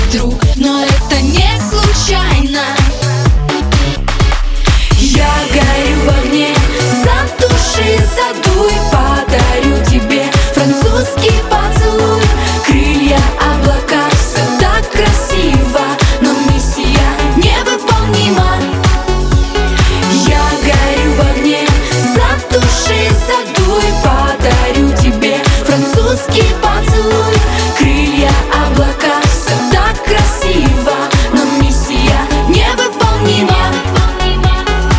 Жанр: Поп музыка / Танцевальные
Pop, Dance